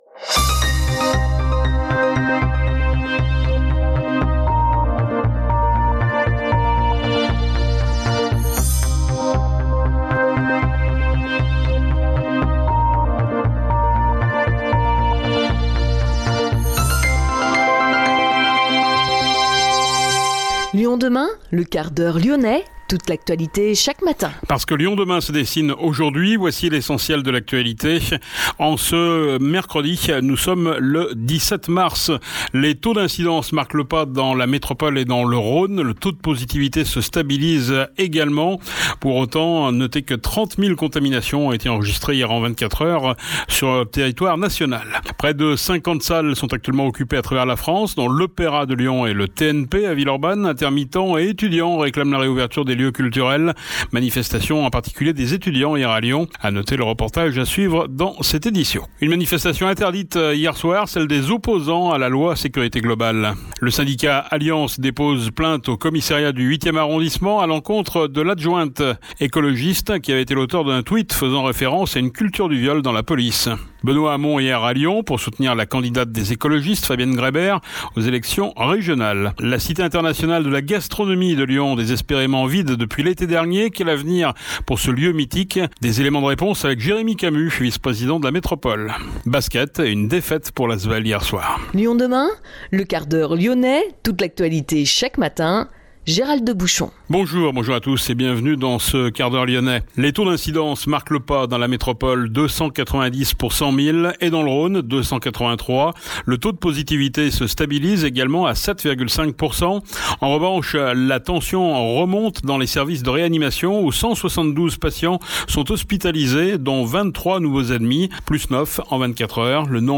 Quel avenir pour la Cité Internationale de la Gastronomie ?Notre invité : Jérémy Camus vice-président de Métropole de Lyon